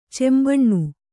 ♪ cembaṇṇu